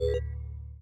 card_hover.wav